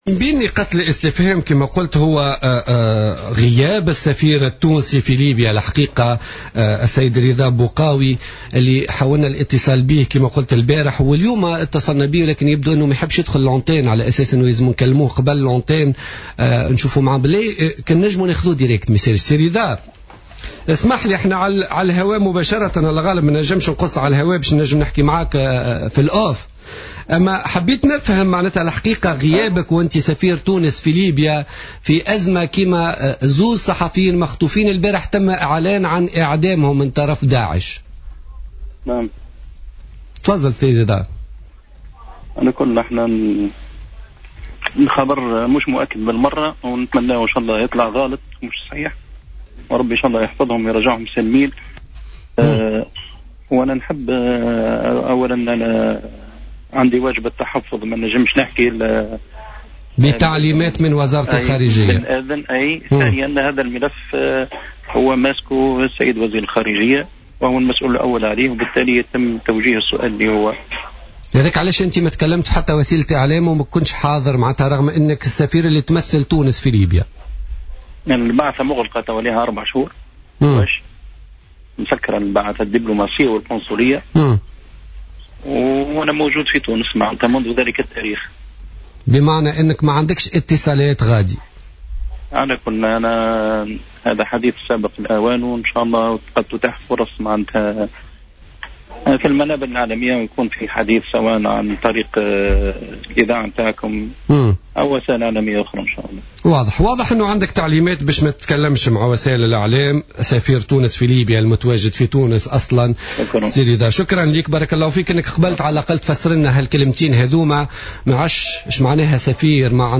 حوار مع السفير التونسي في ليبيا